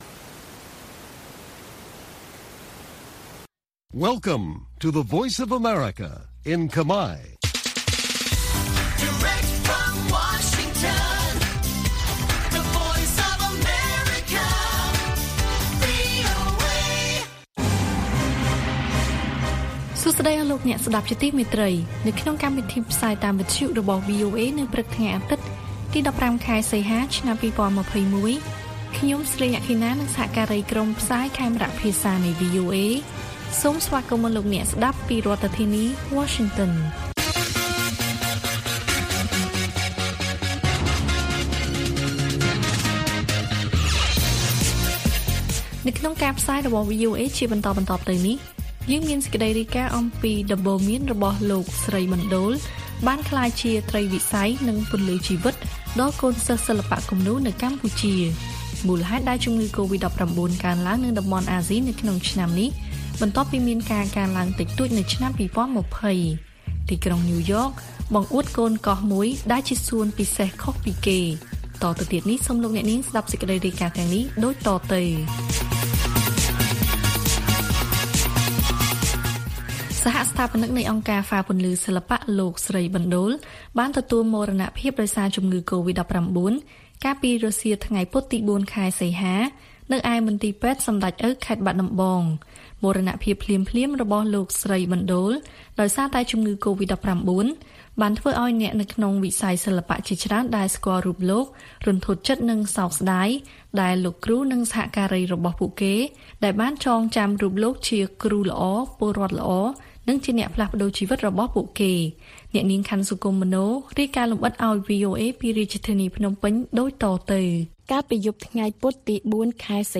ព័ត៌មានពេលព្រឹក៖ ១៥ សីហា ២០២១